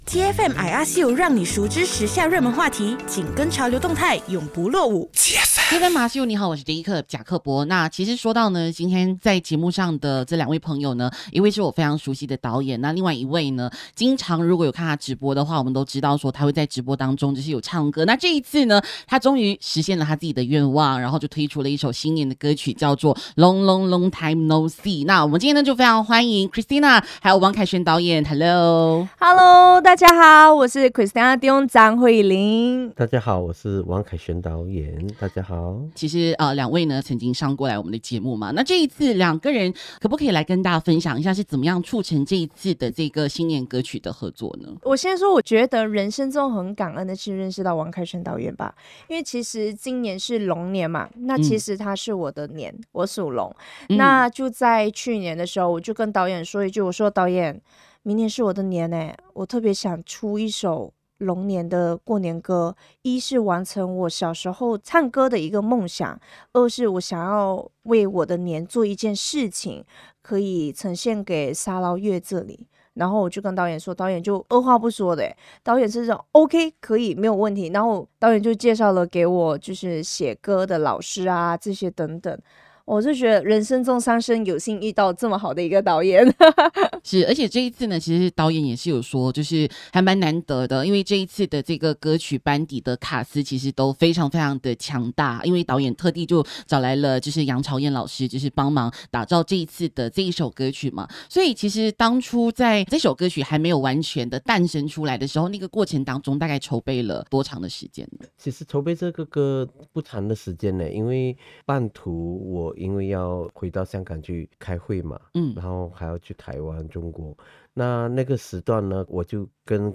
《龙龙龙Time No See》贺岁歌曲访问
0123 TEA FM I ASK U 《龙龙龙Time No See》贺岁歌曲访问.mp3